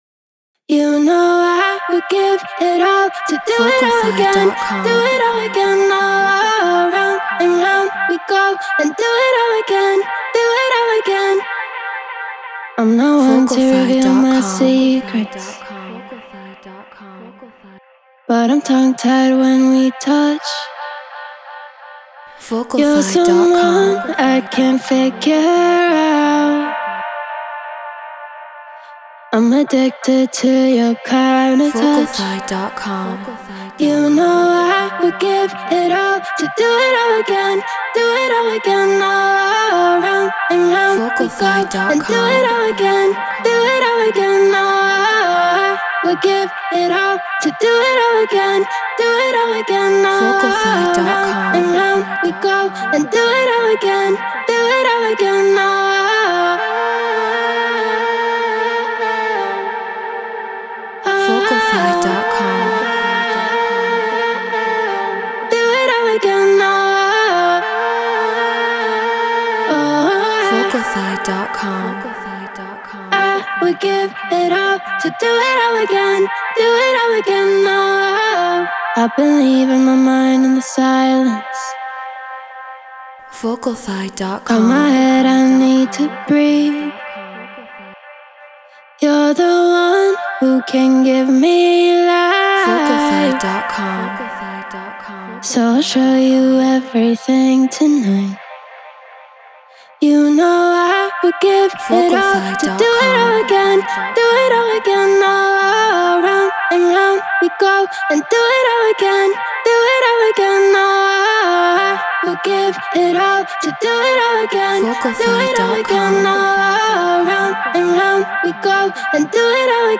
Drum & Bass 174 BPM Gmin
Shure KSM 44 Apollo Twin X Pro Tools Treated Room